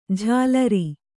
♪ jhālari